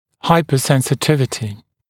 [ˌhaɪpəˌsensɪ’tɪvətɪ][ˌхайпэˌсэнси’тивэти]гиперчувствительность, повышенная чувствительность